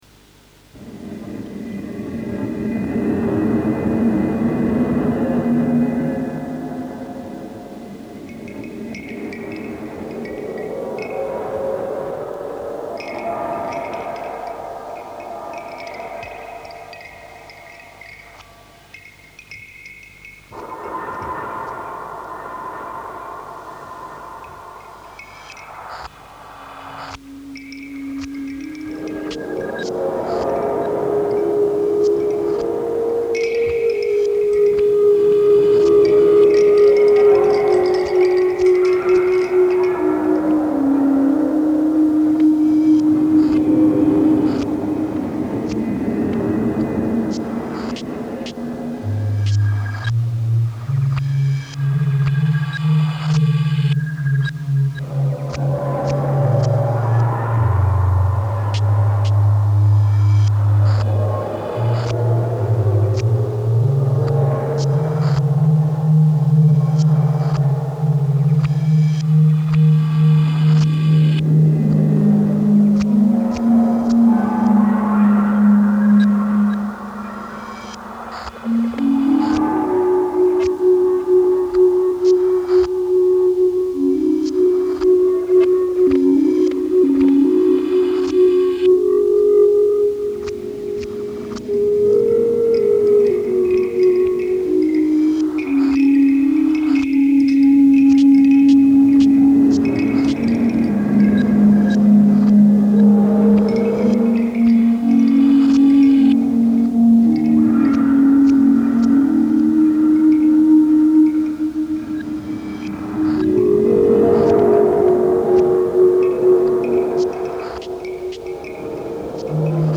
Ambient-Track